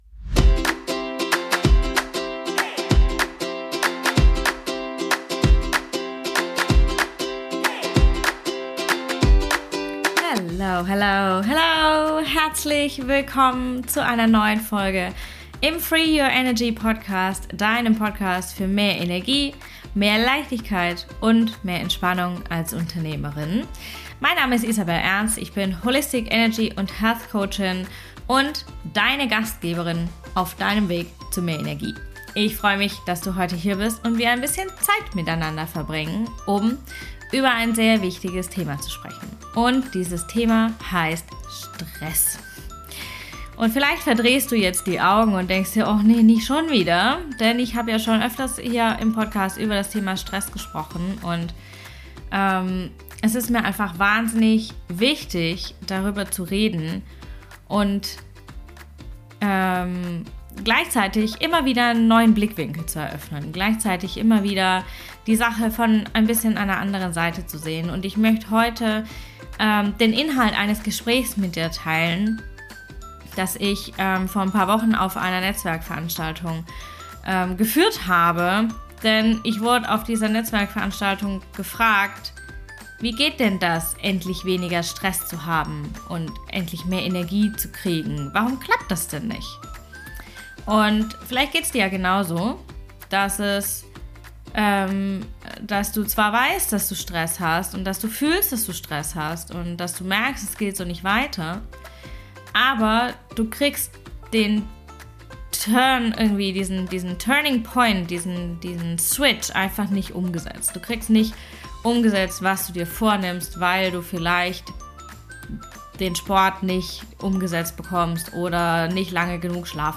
Dann ist diese neue Solo Folge 100% für dich!